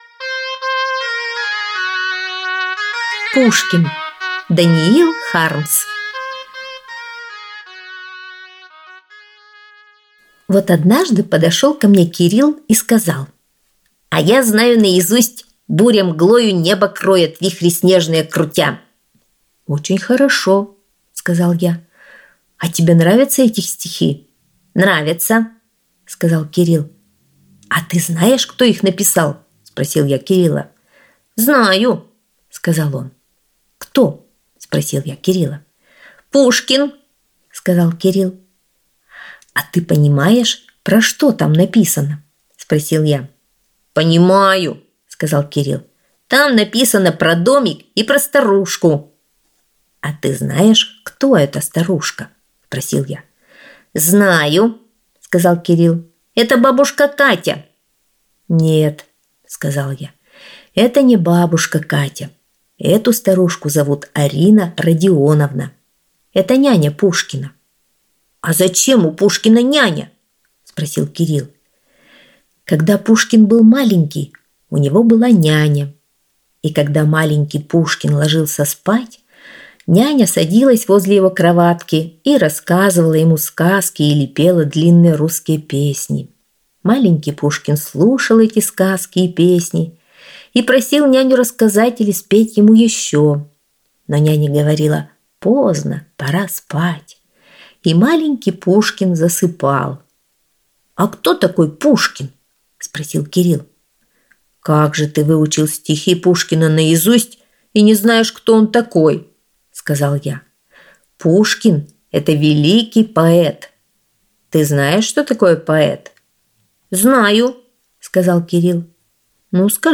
Аудиосказка «Пушкин»